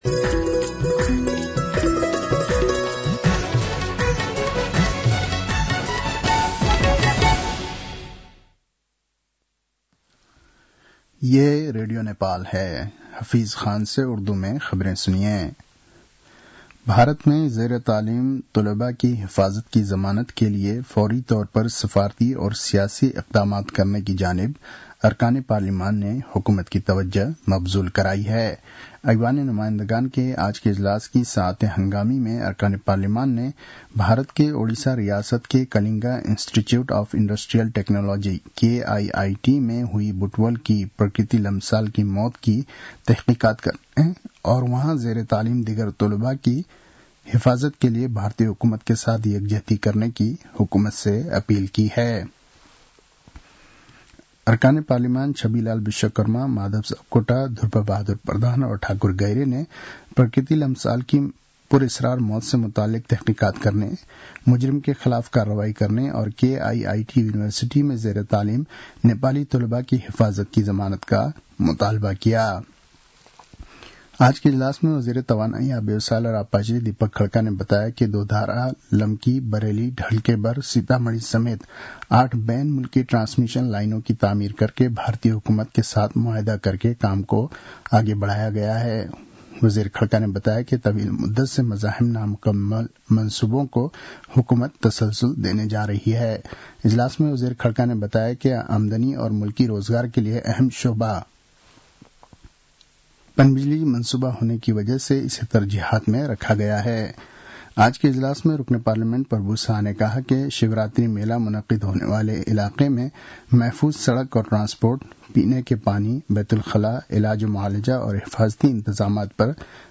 उर्दु भाषामा समाचार : ७ फागुन , २०८१